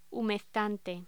Locución: Humectante